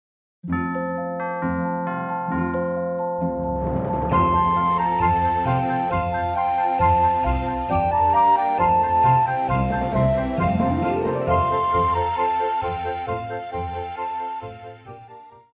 高音直笛
樂團
聖誕歌曲,傳統歌曲／民謠,聖歌,教會音樂,古典音樂
獨奏與伴奏
有主奏
有節拍器